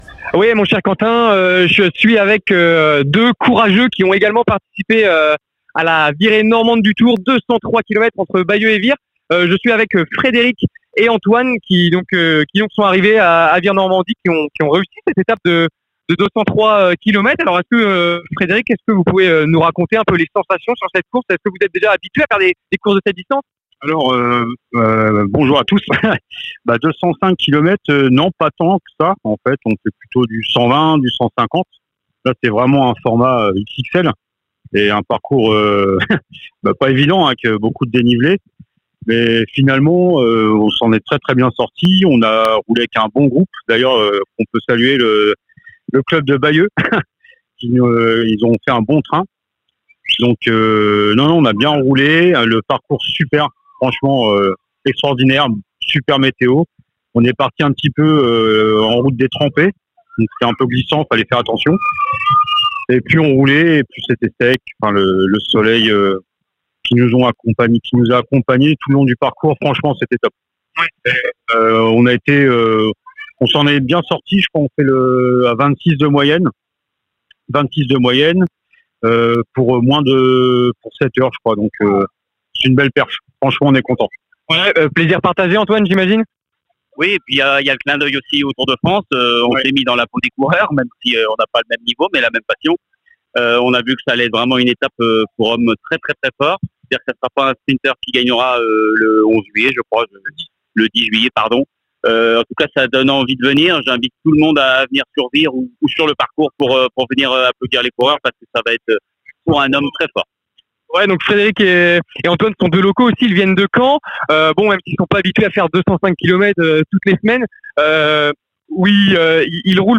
à l'arrivée des Virées Normandes.